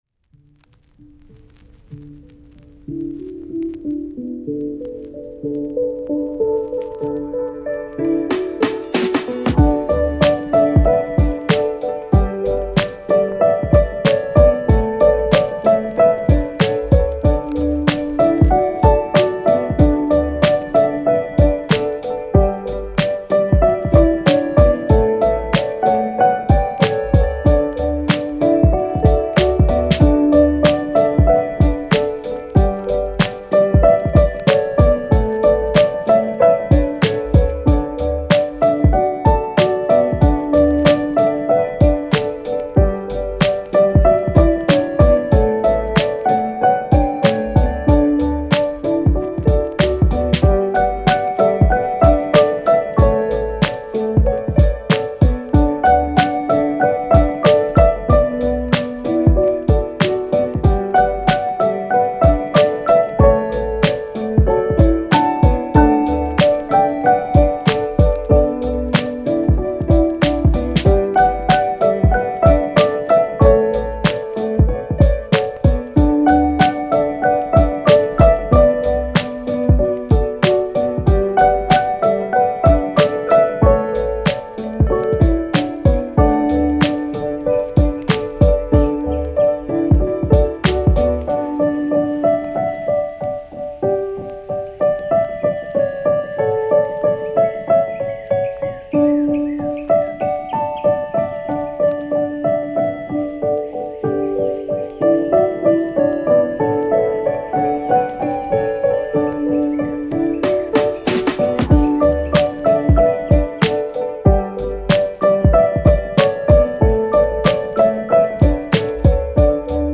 フリーBGM
チル・穏やか